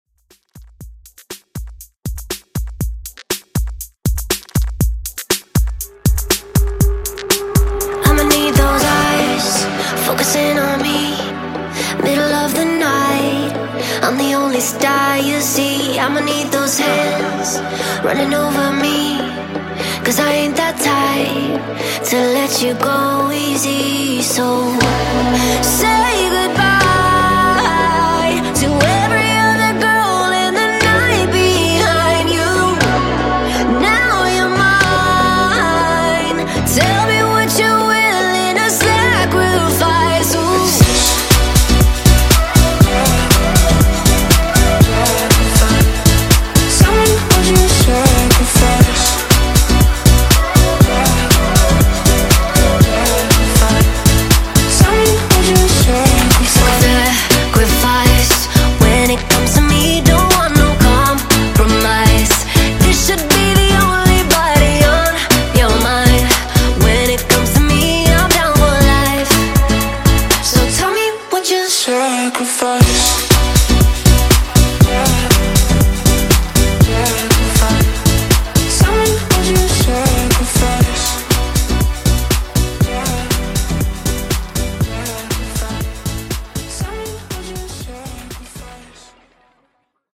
Top40 ReDrum)Date Added